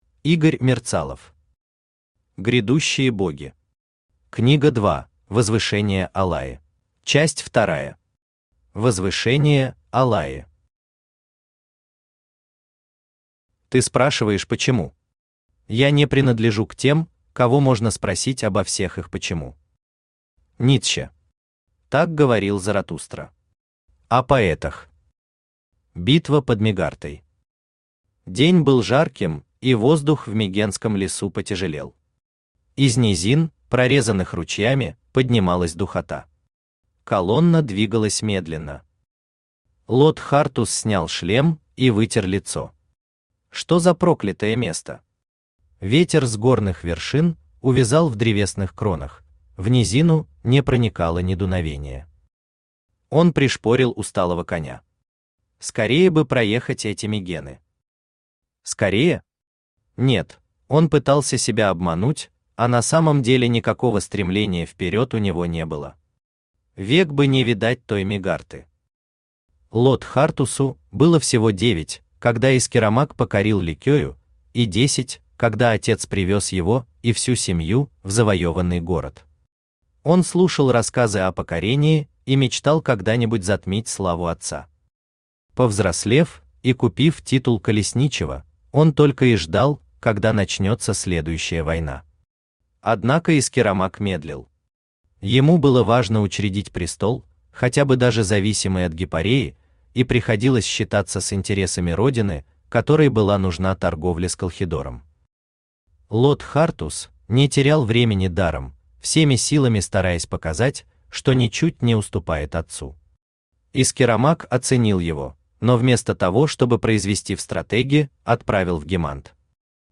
Книга 2: Возвышение Алайи Автор Игорь Валерьевич Мерцалов Читает аудиокнигу Авточтец ЛитРес.